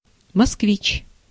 Ääntäminen
IPA : /ˈmʌs.kəˌvaɪt/